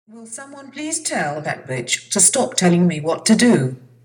Voice Response
This is one of her British voices, she also speaks Italian, French, Russian, German, Polish, Norwegian, Portugese, Dutch, Greek, Swedish, Spanish, Catalan, Chinese and Arabic.